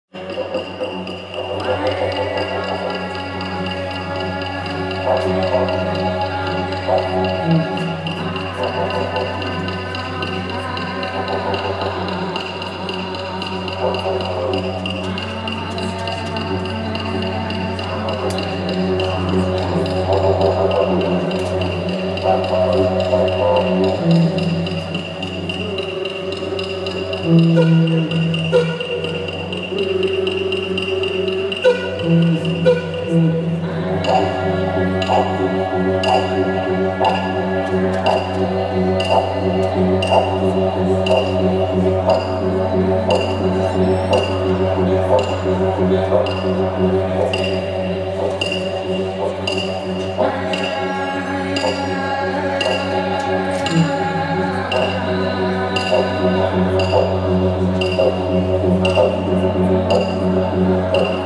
Listen to some of the pre-game Indigenous Round ceremony held before the Sydney Swans v Geelong clash